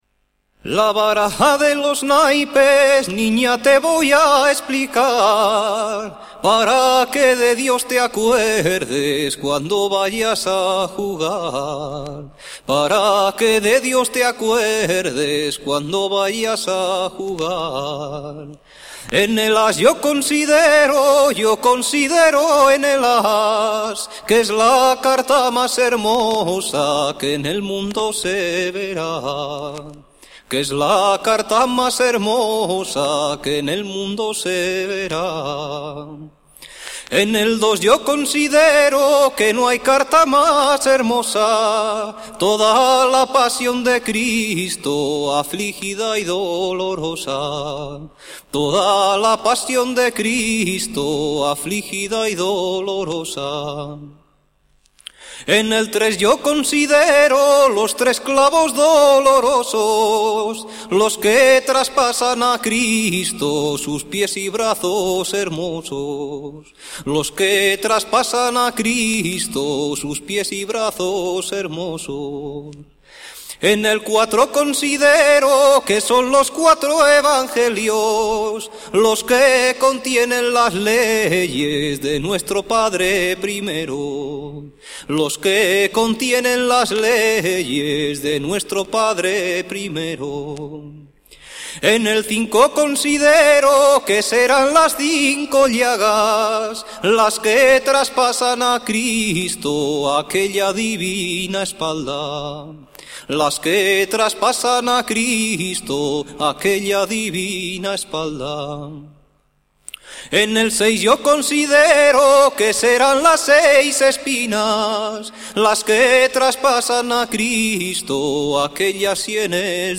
Rabel, zanfona y sonajas